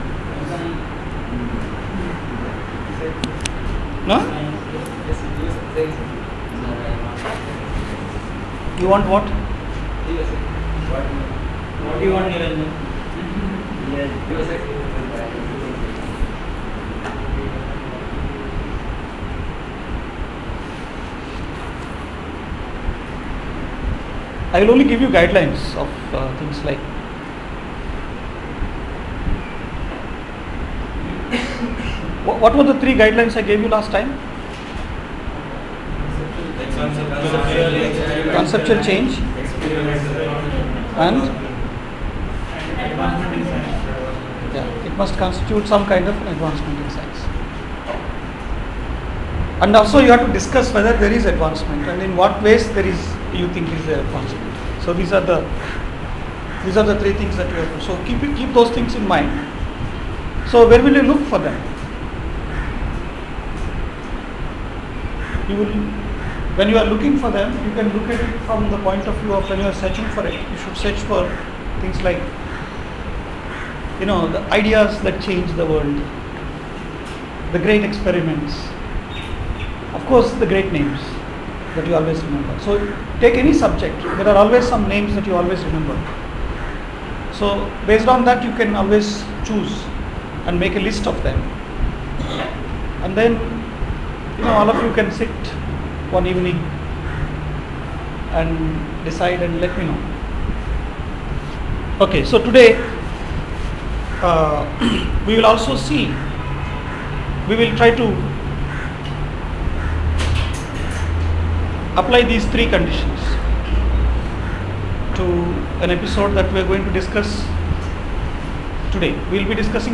lecture 16 — Invitation to History of Science: H201